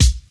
• Good Kickdrum C Key 61.wav
Royality free kick drum one shot tuned to the C note. Loudest frequency: 1963Hz
good-kickdrum-c-key-61-rOu.wav